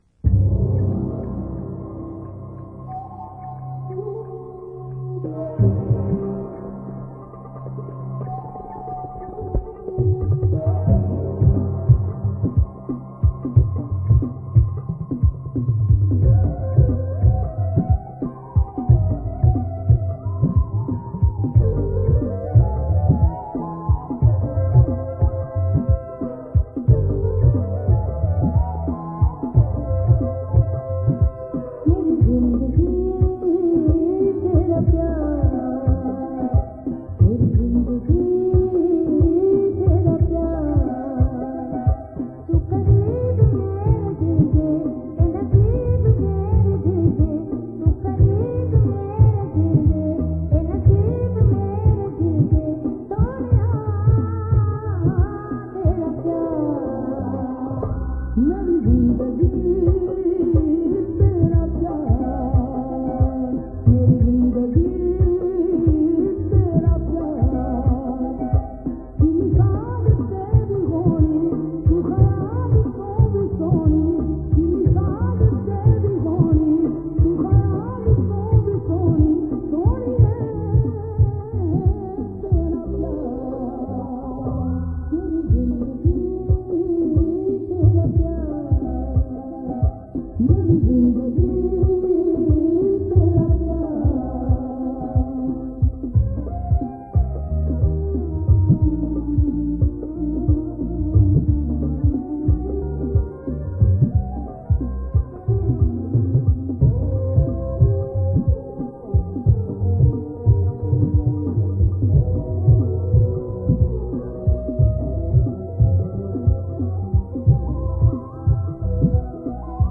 Sufi Collection